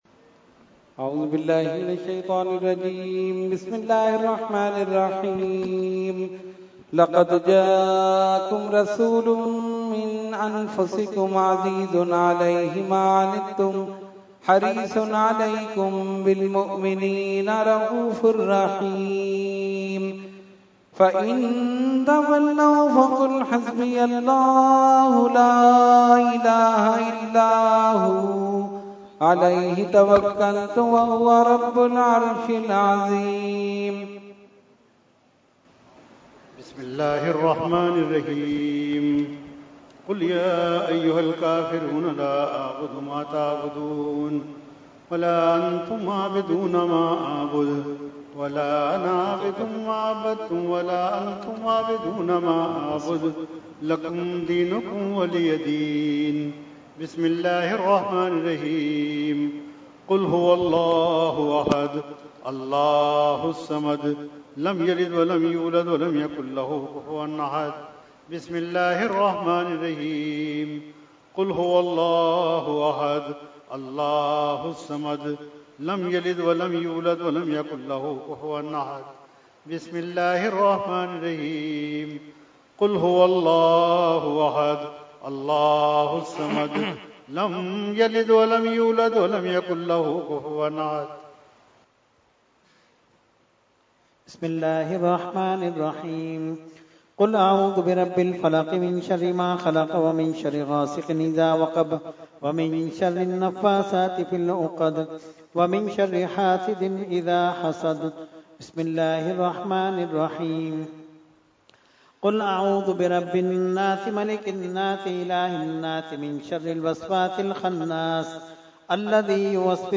Category : Fatiha wa Dua | Language : ArabicEvent : Urs Ashraful Mashaikh 2018